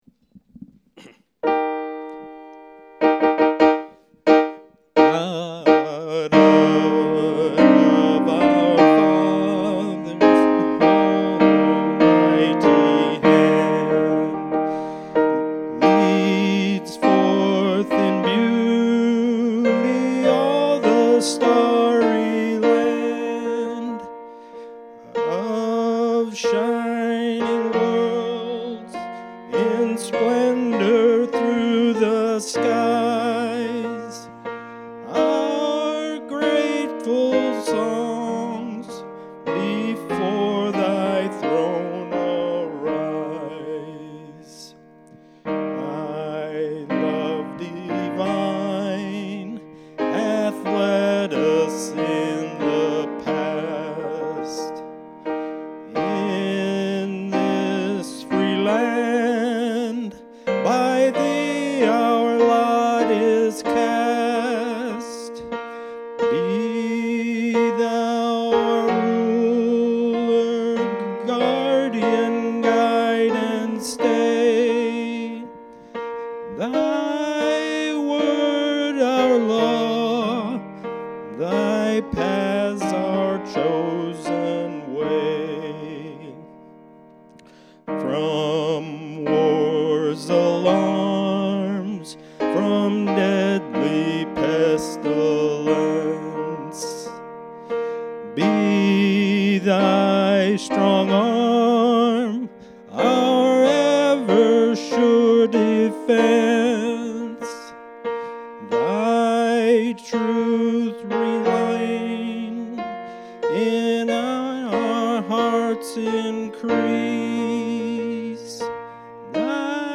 November 10 Worship Service & Communion